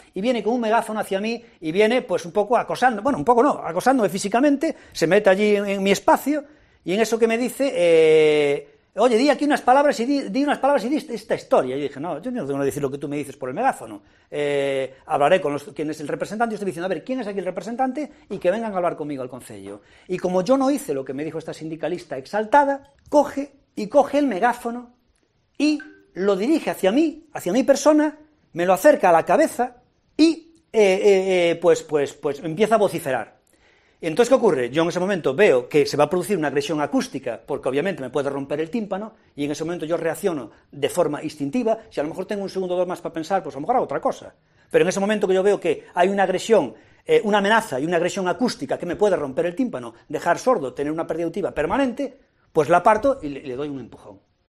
El alcalde de Ourense explica el incidente.